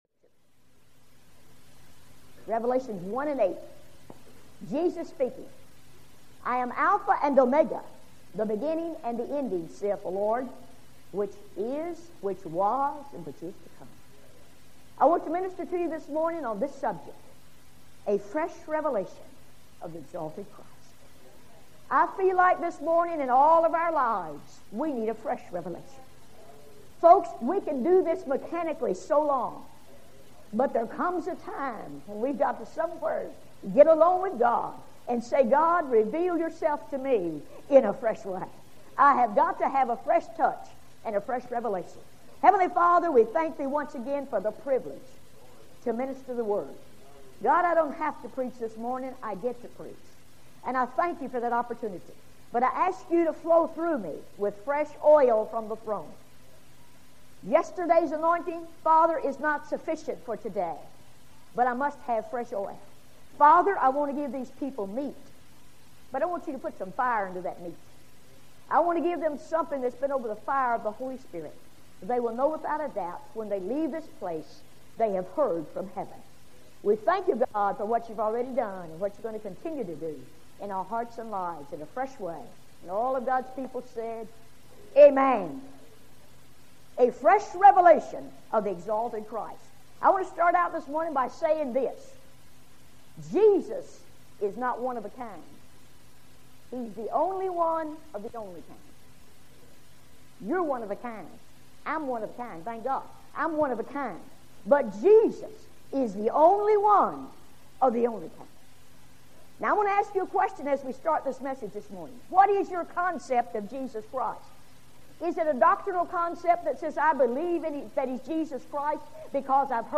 Women Preachers